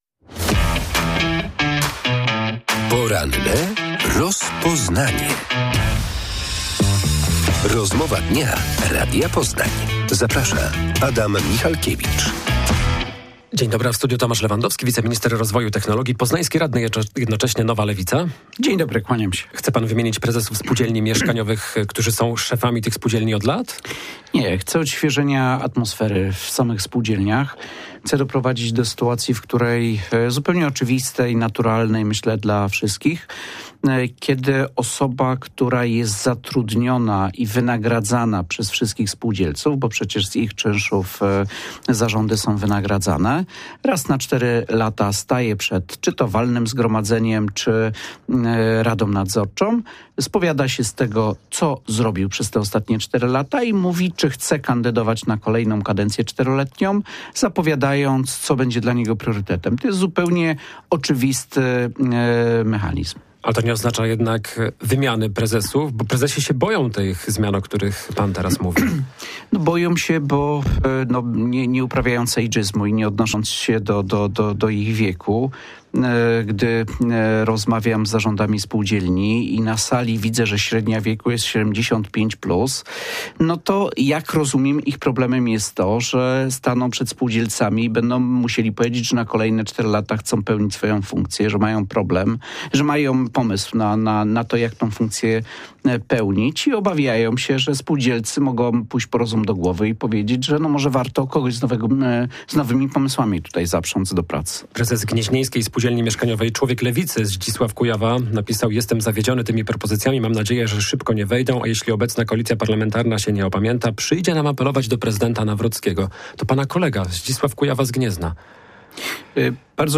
W porannej rozmowie Radia Poznań wiceminister rozwoju Tomasz Lewandowski z Nowej Lewicy przekonywał, że chce doprowadzić do sytuacji, w której raz na cztery lata osoba, która jest zatrudniona i wynagradzana przez wszystkich spółdzielców, spowiada się z tego, co zrobiła przez ostatnie cztery lata i mówi, czy chce kandydować na kolejną kadencję.